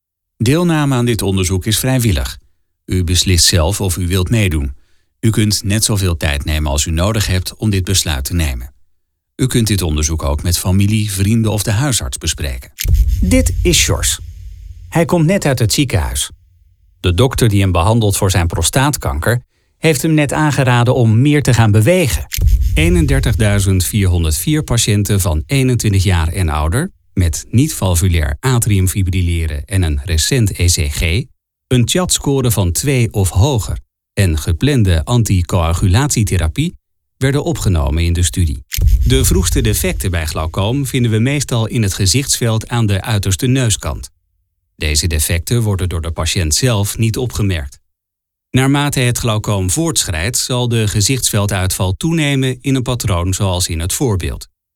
Authentic Dutch voice-over with a warm tone, fast delivery and a relaxed, professional approach
[Dutch] Medical narration for both patients and professionals
Middle Aged